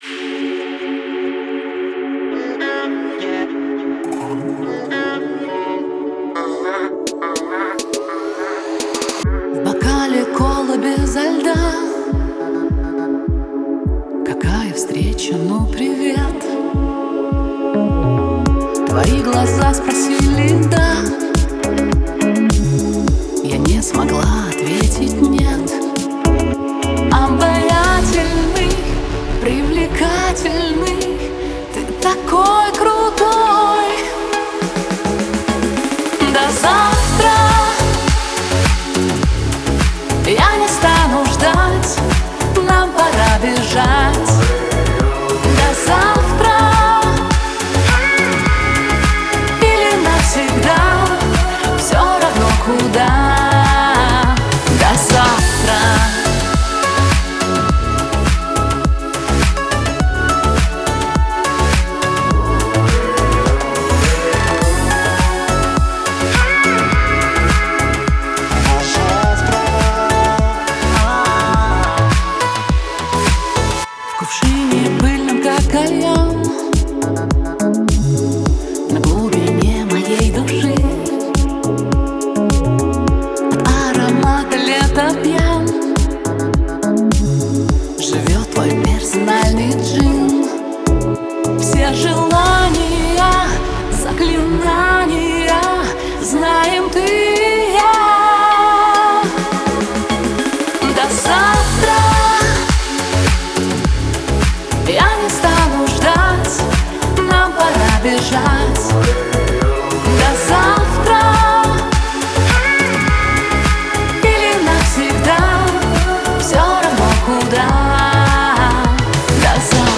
это трогательная композиция в жанре поп